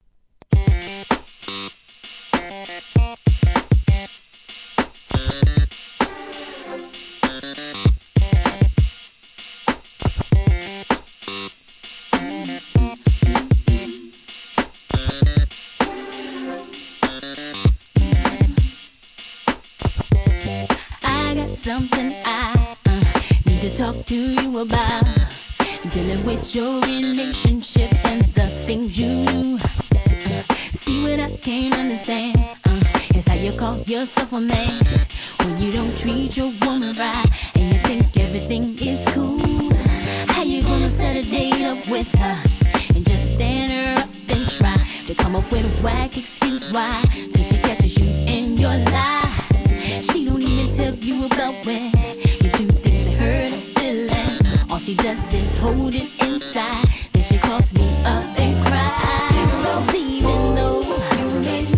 HIP HOP/R&B
しっかり時代を意識したフロア対応なプロダクション!